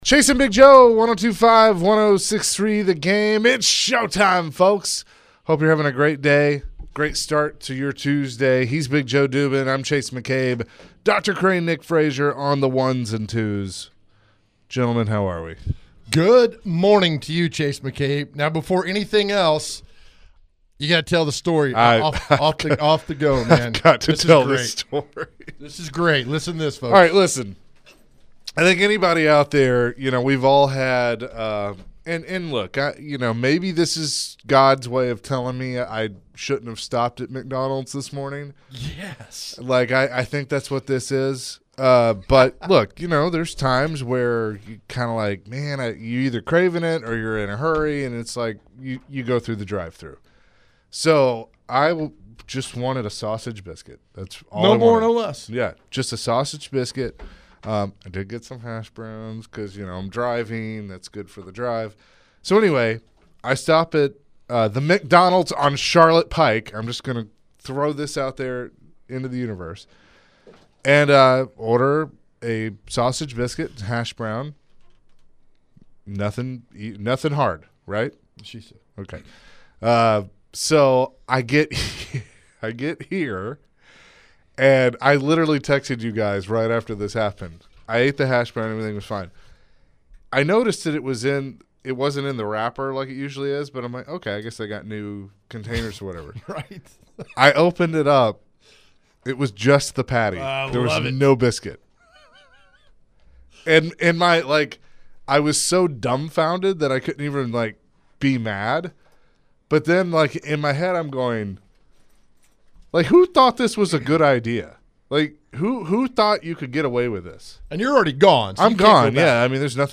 WHat would you ask Brian Callahan at his press conference. Later in the hour, Brian Callahan spoke to media.